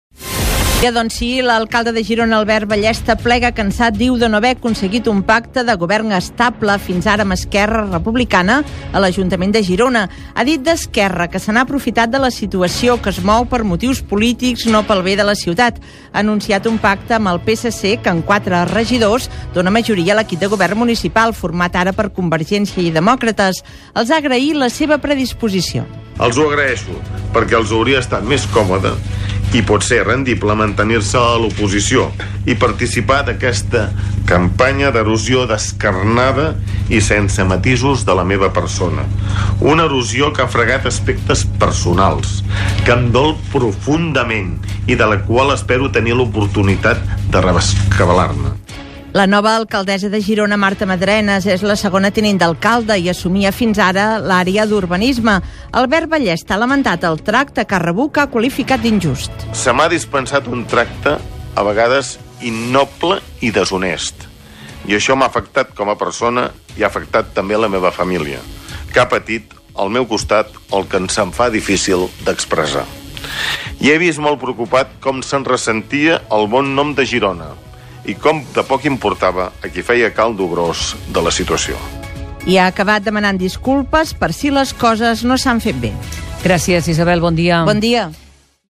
Informatius: crònica municipal - Catalunya Ràdio, 2016
Àudios: arxiu sonor i podcast de Catalunya Ràdio